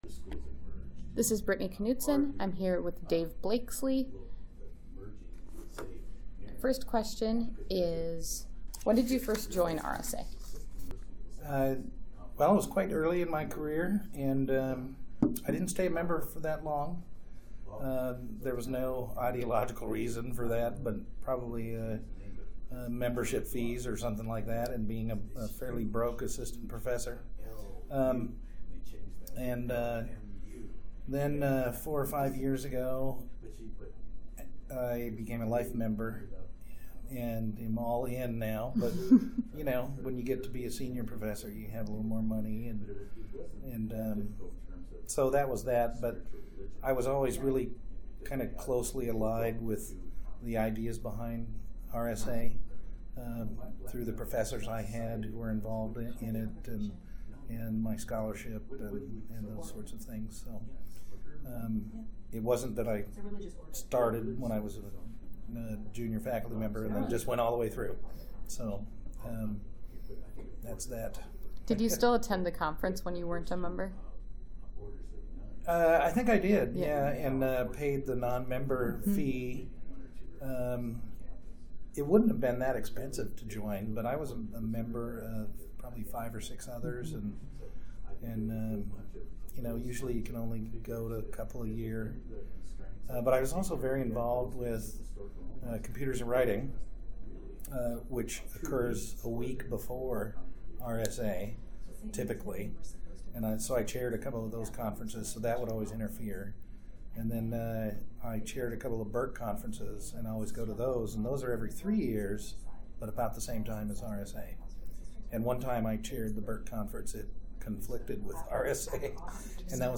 Oral History Interview
2018 RSA Conference in Minneapolis, Minnesota